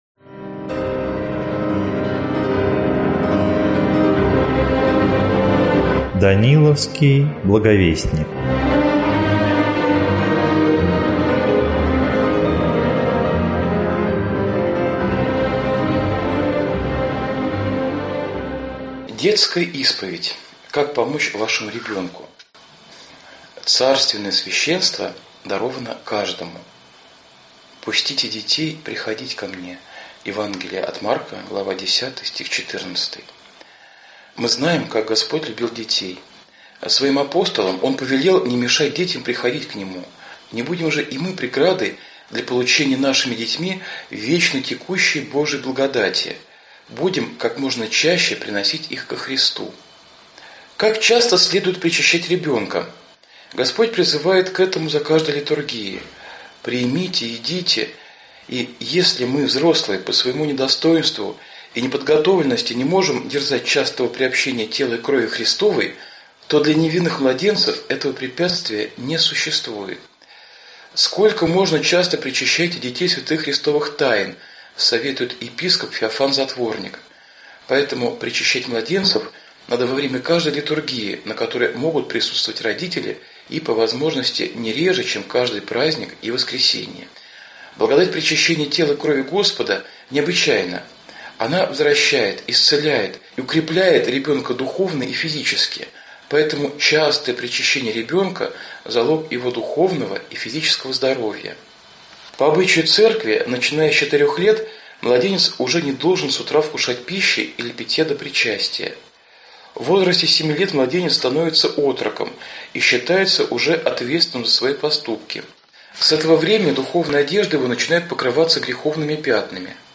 Аудиокнига Детская исповедь. Как помочь ребенку | Библиотека аудиокниг